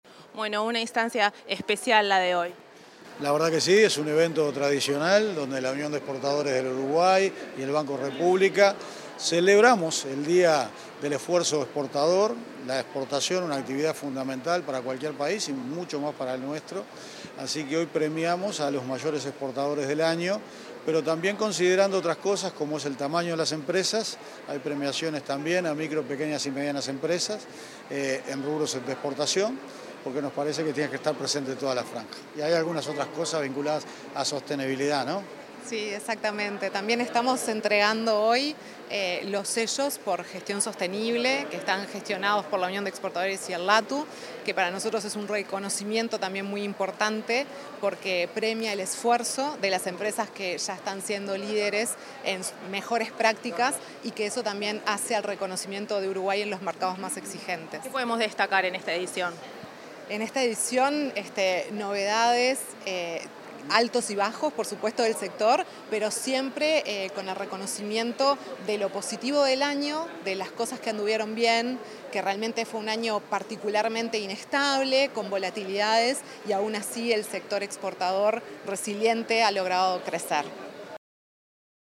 Declaraciones del presidente del BROU, Álvaro García
Durante la ceremonia de reconocimiento al Esfuerzo Exportador 2025, el presidente del Banco de la República Oriental del Uruguay, Álvaro García, y la